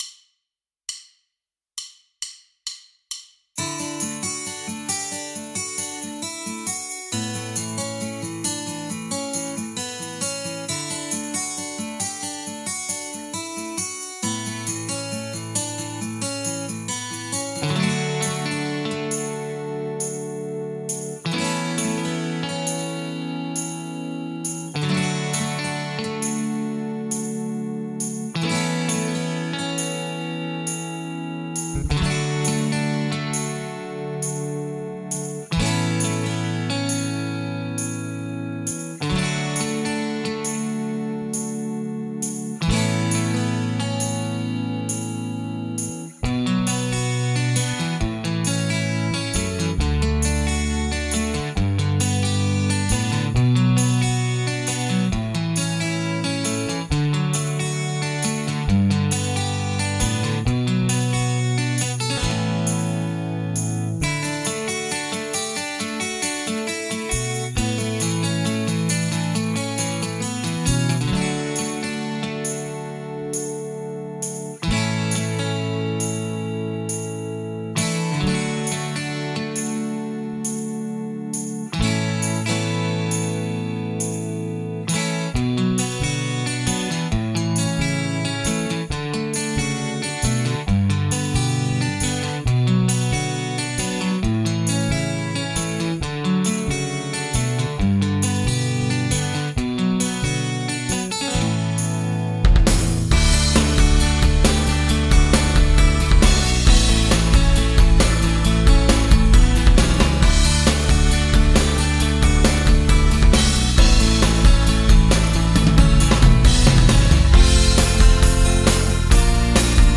Playback, Karaoke, Instrumental, Midi